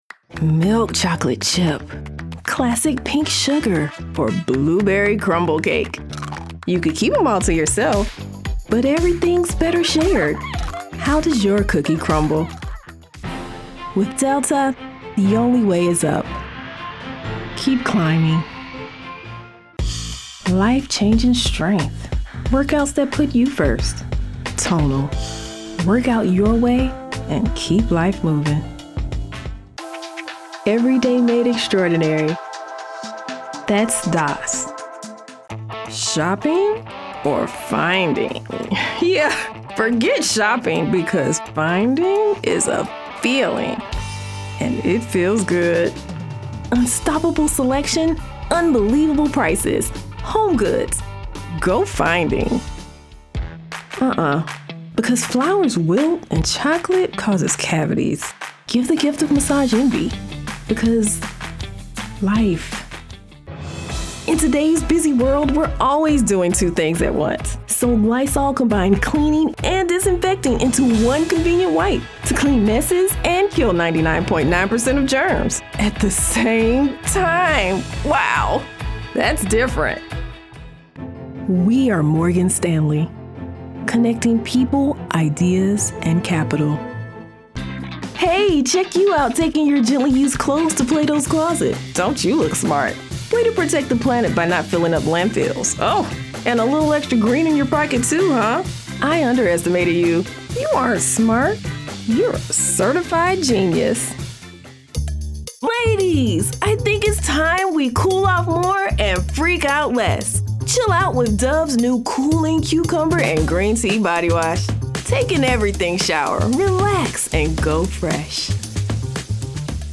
His voice has a reassuring confidence with an energetic delivery. He can be your friendly guy next door, a business professional, a zany cartoon character, a brash video game anti-hero and more!